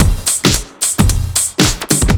OTG_TripSwingMixC_110b.wav